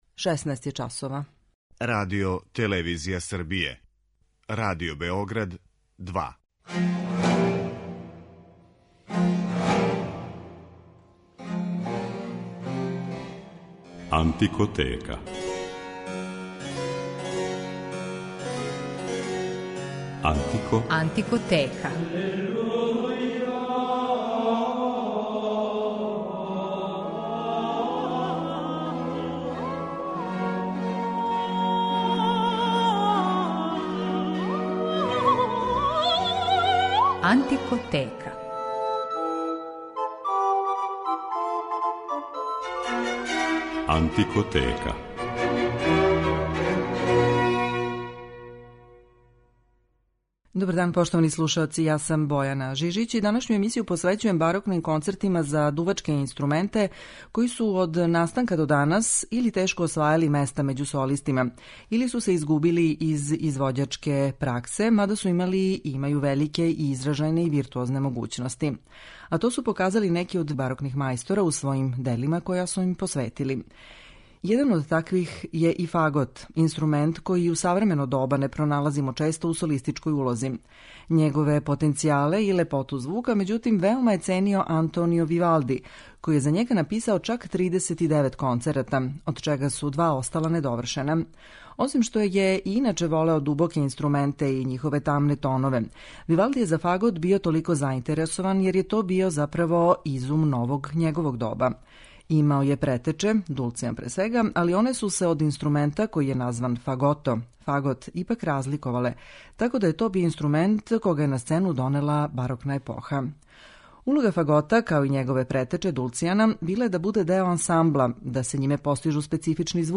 Емисија је посвећена барокним концертима за дувачке инструменте
Њихове потенцијале су запазили, истражили и искористили многи барокни великани, попут Антонија Вивалдија, Кристофа Граупнера, Јохана Фридриха Фаша и Георга Филипа Телемана, и њихове концерте за фагот, обоу д`аморе, ловачки рог и шалмај моћи ћете данас да слушате.